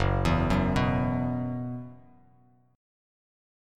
F#7sus4 chord